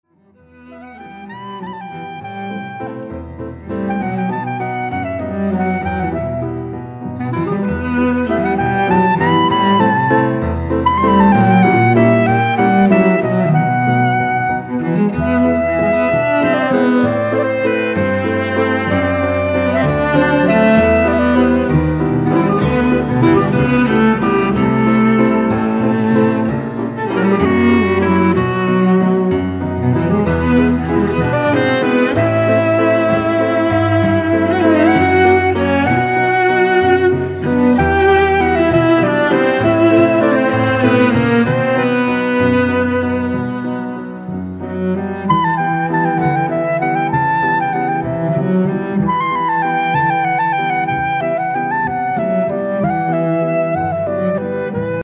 und Computersounds, eingängig und atmosphärisch dicht.
Altsaxofon, Bb-Klarinette, Piano, chromatische Mundharmonika
Viola
Cello
Gitarre